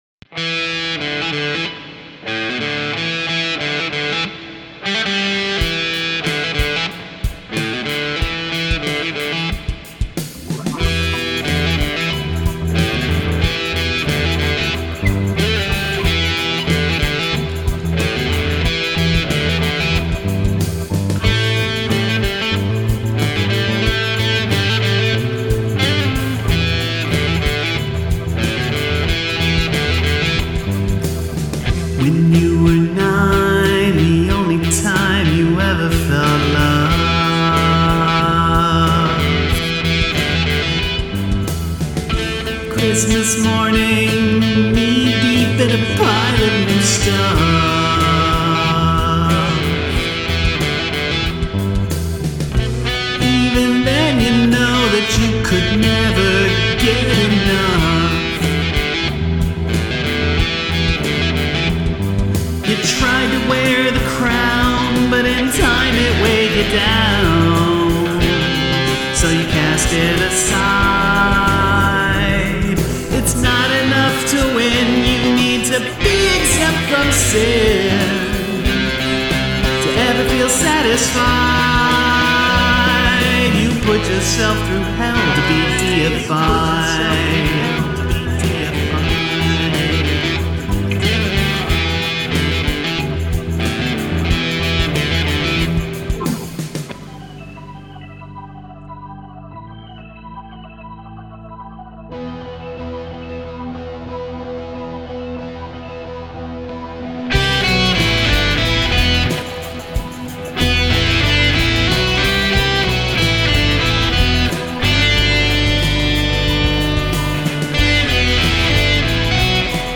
The palindrome guitar solo / breakdown is cool, however.